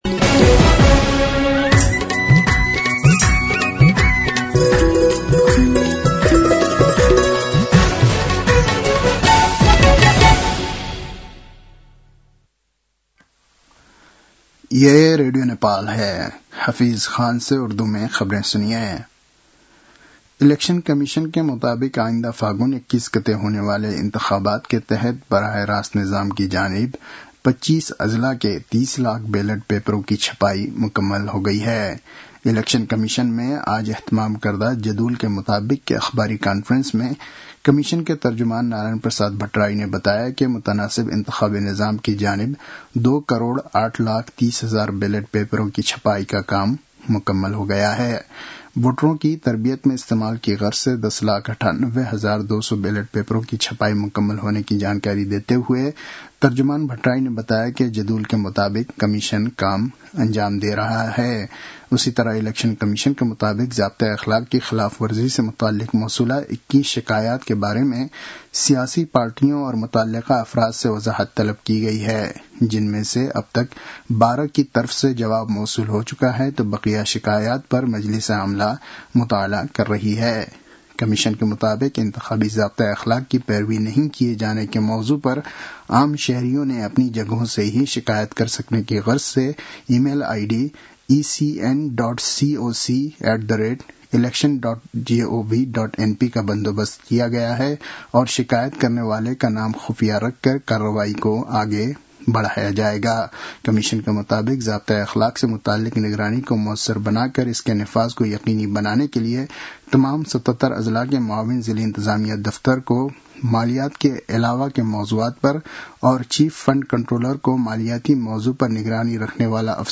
उर्दु भाषामा समाचार : १८ माघ , २०८२